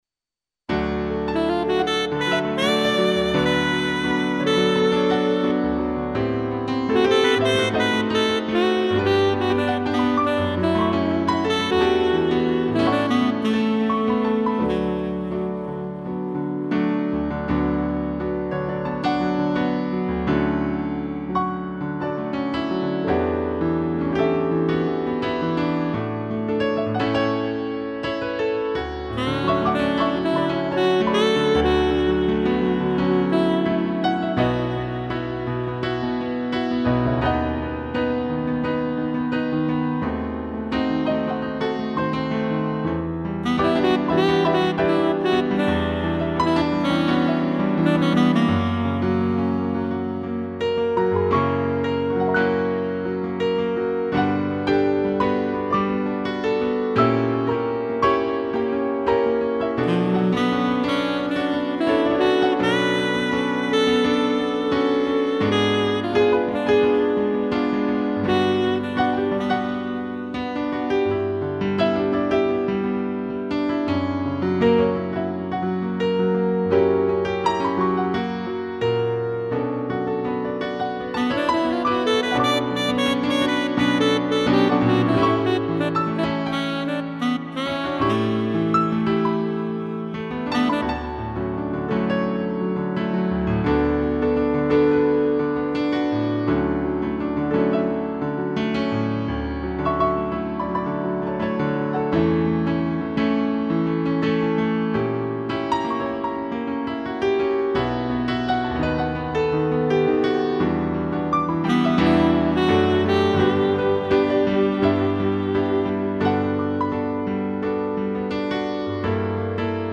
2 pianos e sax
instrumental